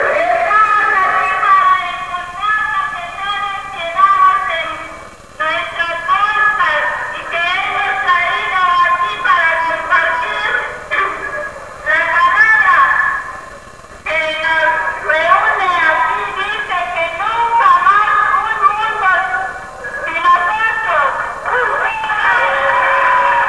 during the Opening Ceremony of The 2nd Encounter